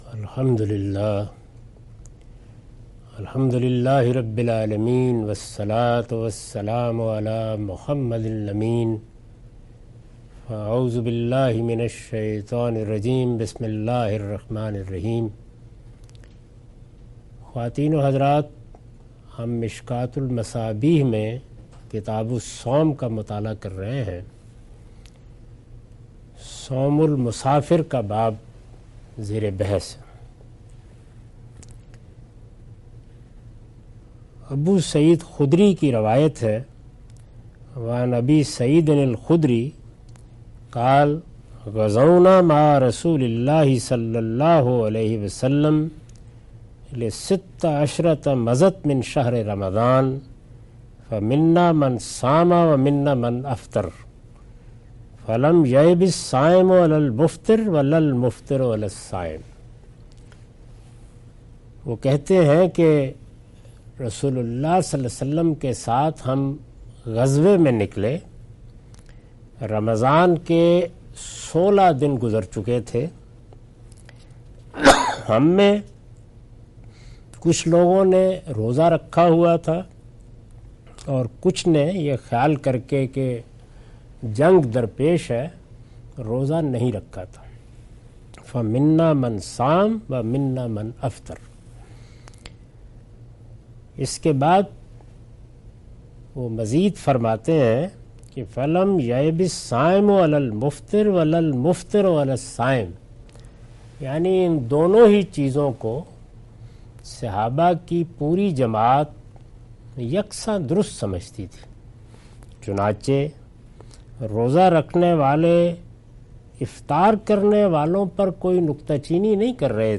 Sayings of the Prophet , Questions & Answers